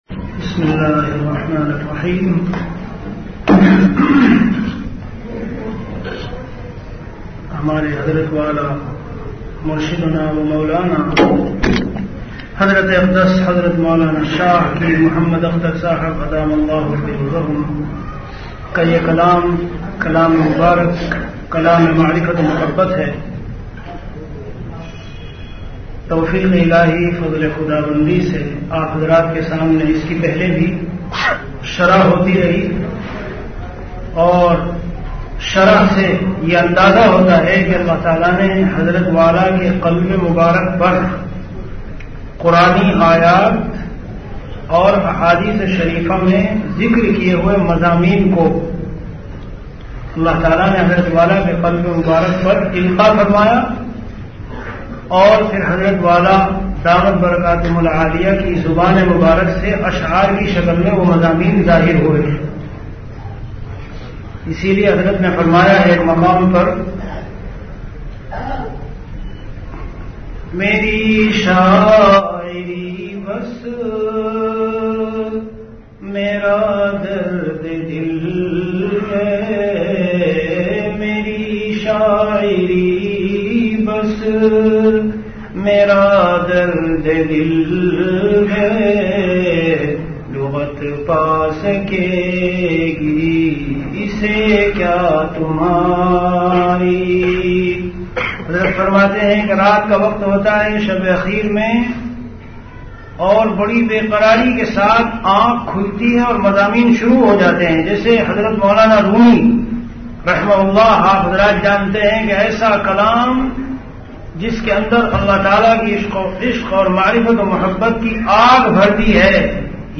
An Islamic audio bayan
Delivered at Khanqah Imdadia Ashrafia.